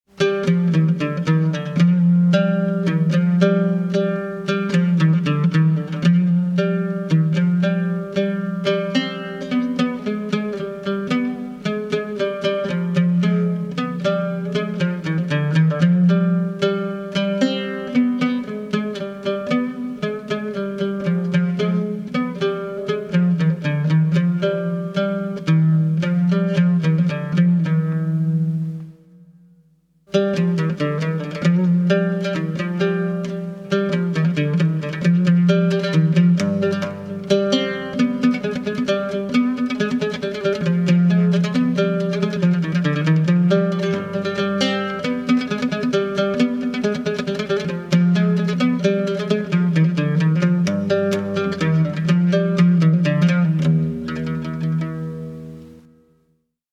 Découvrez une méthode unique pour apprendre le Oud